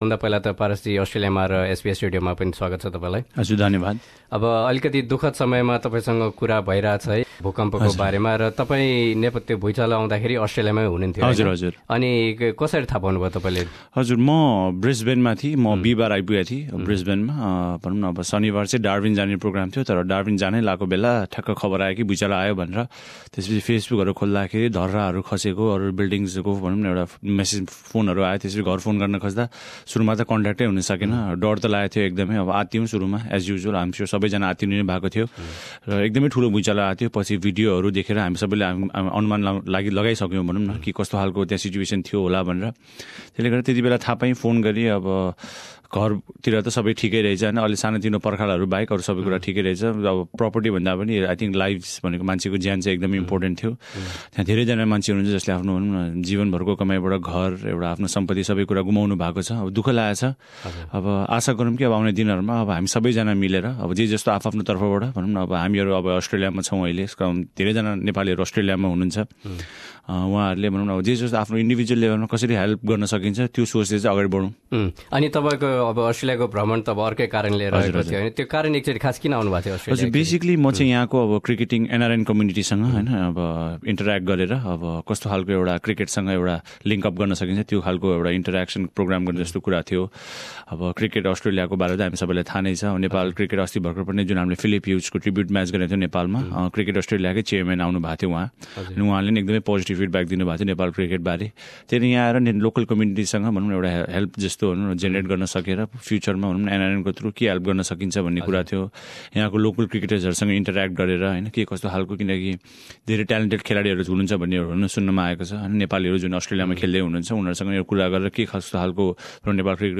भूकम्प पीडितका लागि दिइएका चन्दा उनीहरुले नै पाएको निश्चित गर्ने जिम्मा आफूले लिएको बताएका छन्, नेपाली क्रिकेट कप्तान पारस खड्काले। साथै, भूकम्प पछि नेपाली क्रिकेटमा परेको प्रभाव लगायतका विषयबारे उनले एसबीएस नेपालीसँग कुरा गरेका थिए।